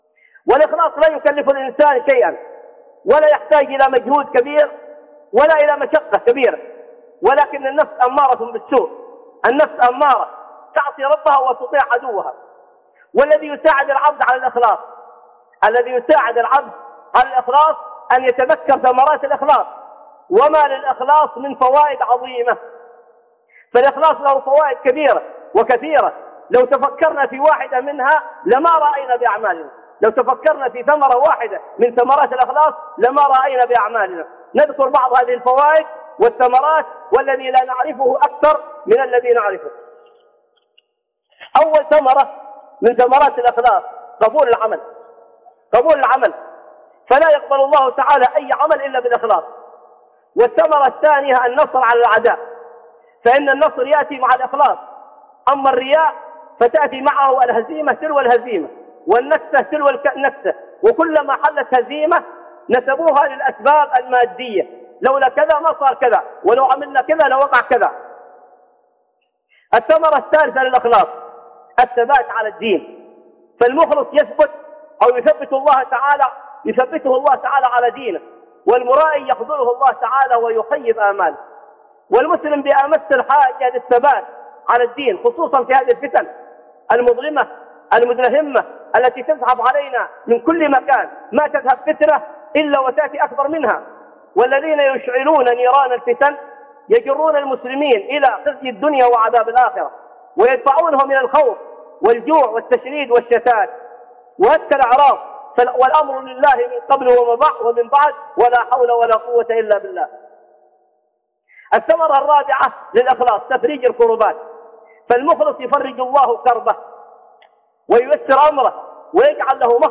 من ثمرات الإخلاص - خطب